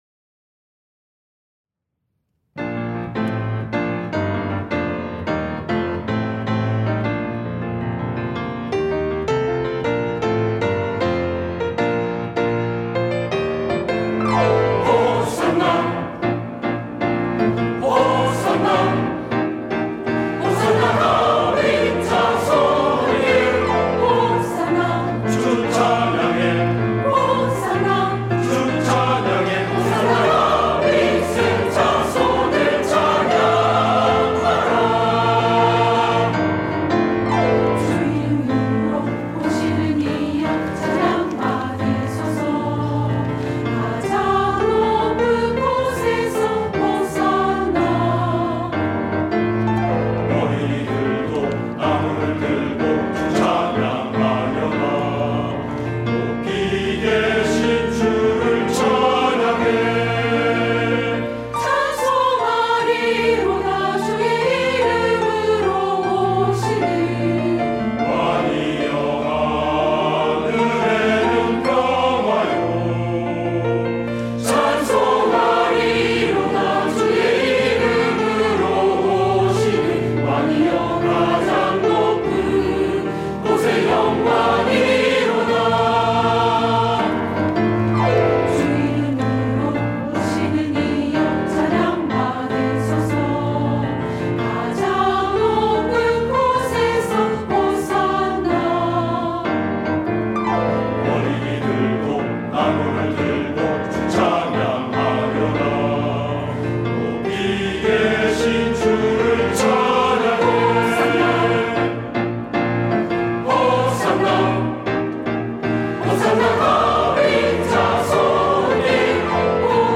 시온(주일1부) - 호산나
찬양대